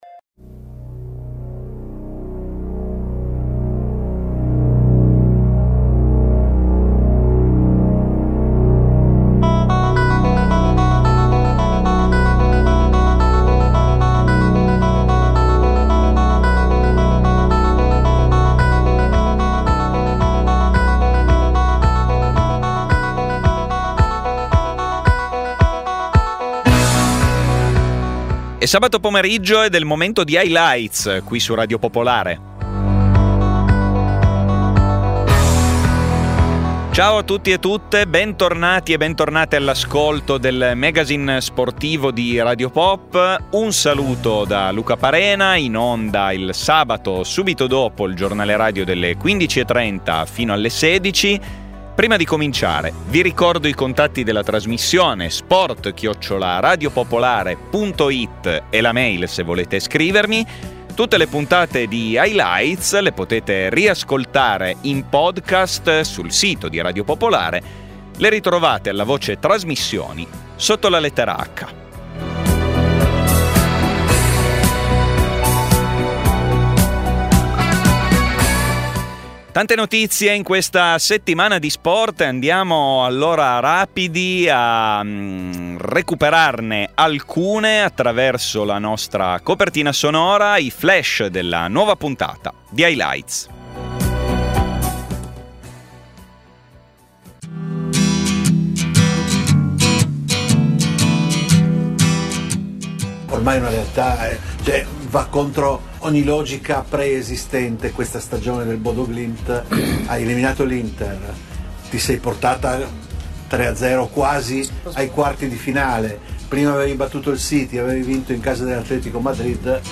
Un'intervista al di sopra del Circolo Polare Artico per fare meglio conoscenza con la realtà del Bodø Glimt.